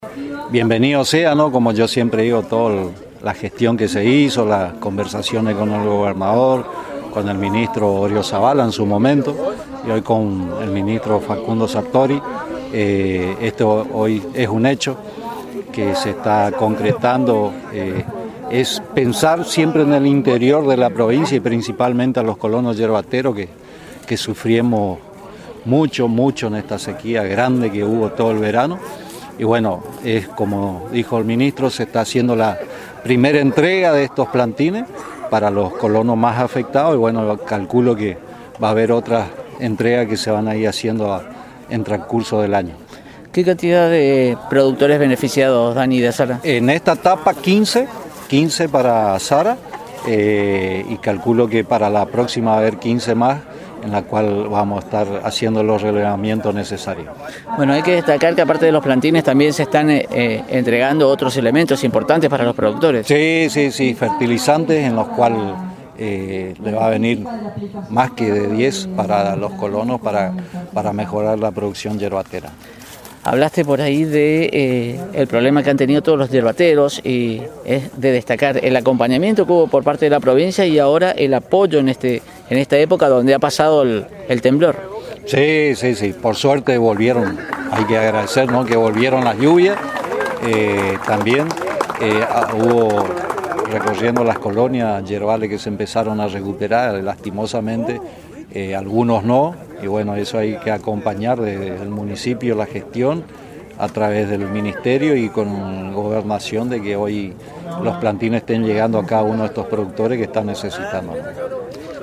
El Intendente de Azara, en charla con Radio Elemental sostuvo